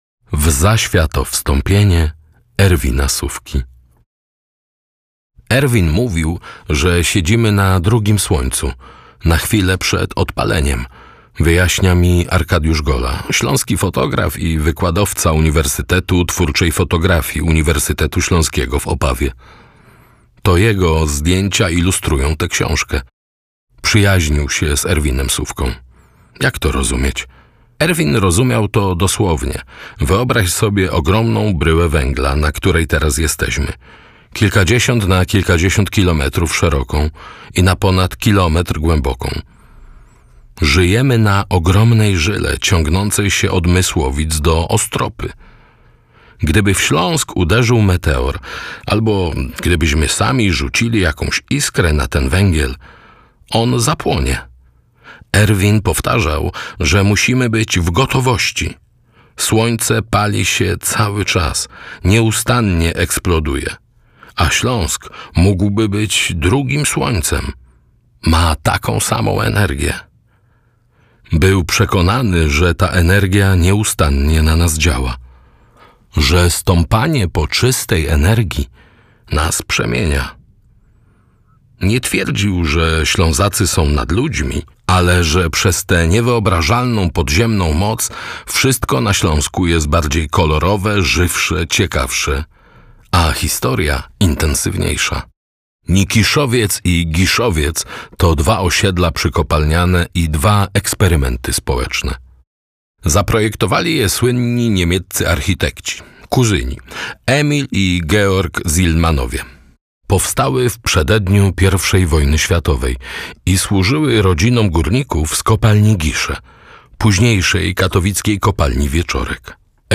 Aglo. Banką po Śląsku - Zbigniew Rokita - audiobook